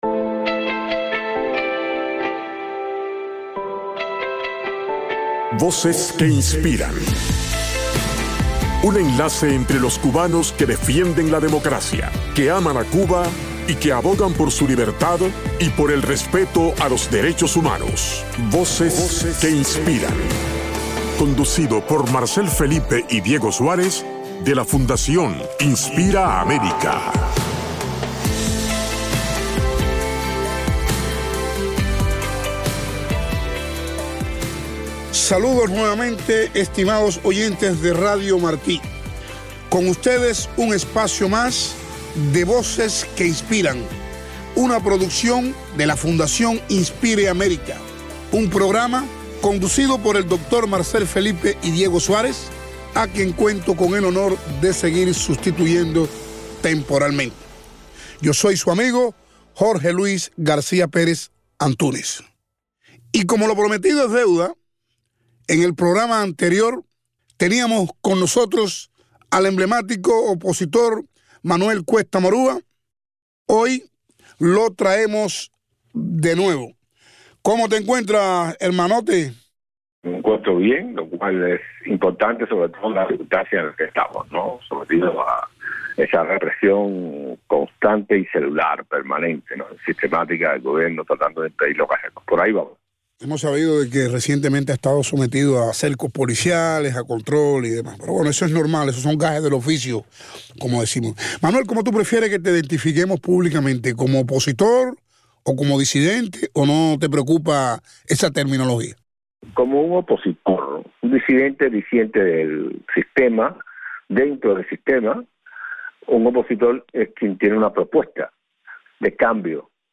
Entrevista a Manuel Cuesta Morúa - Parte II